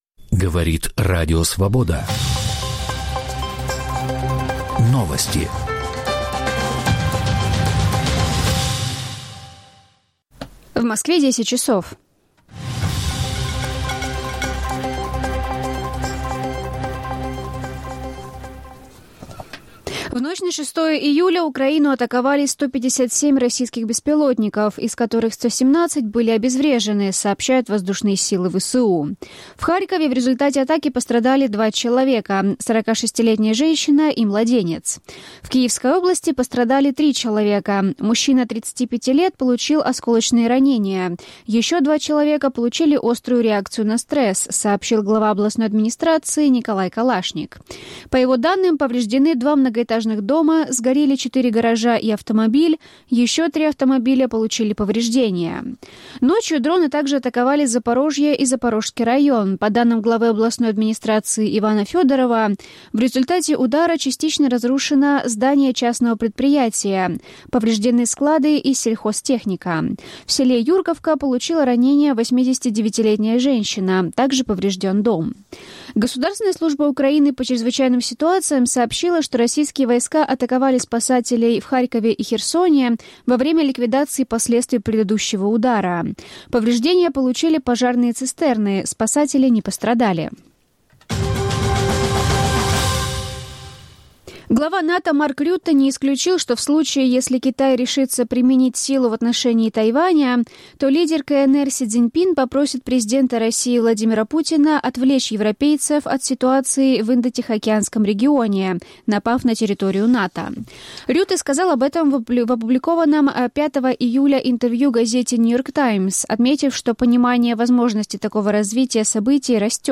Подробности в выпуске новостей:
Аудионовости